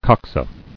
[cox·a]